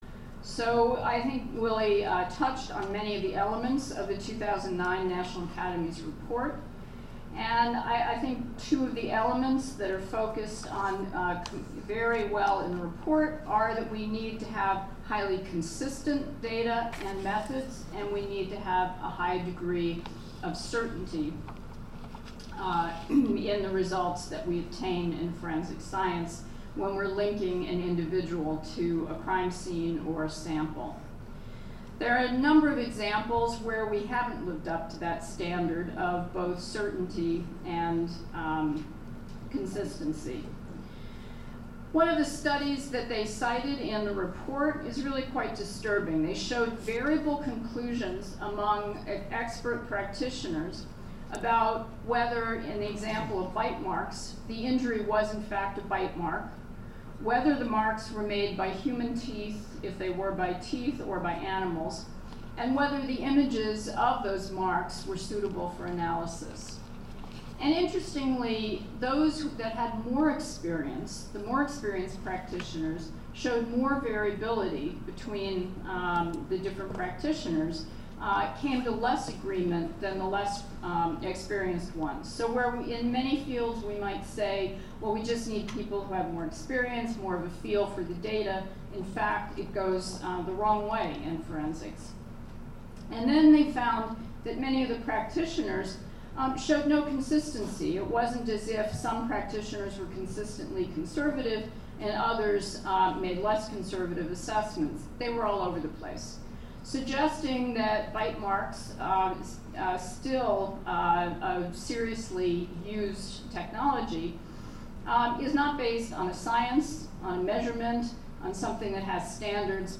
On Tuesday, July 21, the White House OSTP issued an oral presentation by:
Her remarks were presented at the International Symposium on Forensic Science Error Management – Detection, Measurement and Mitigation, Arlington, VA, July 20-24, 2015, organized by the National Institute of Standards and Technology (NIST).
In closing: Here is the avi recording of what Dr. Handlesman said.